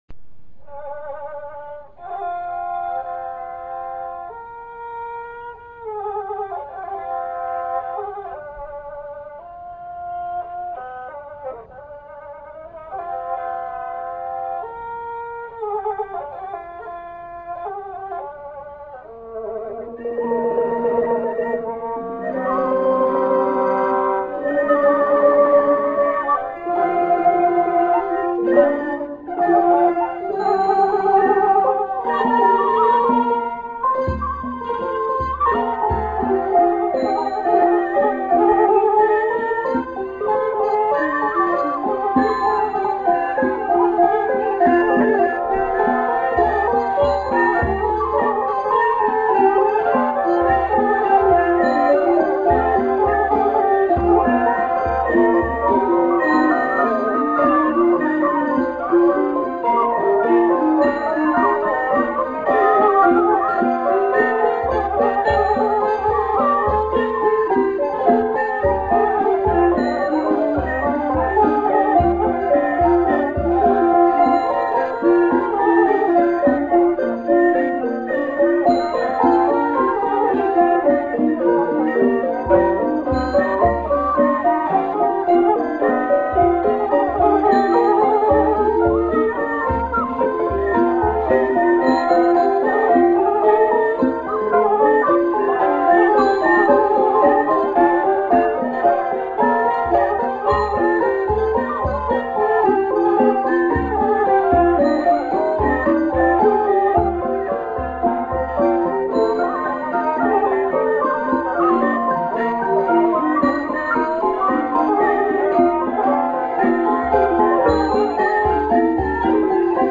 Mahori Khryang Lek
Mahori Khryang Lek: It’s a small size Mahori Orchestra comprising the following instruments:
One Saw-Duang
One Khlui Phiang Aw
(And other percussion usually added.)